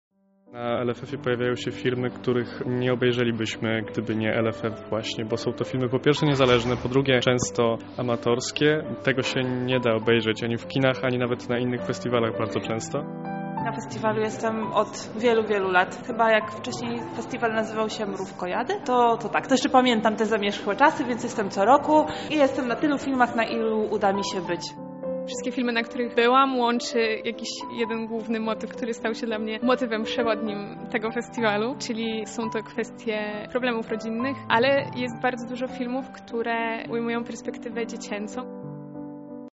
O wrażenia po pierwszym weekendzie festiwalu zapytała odwiedzających LFF nasza reporterka.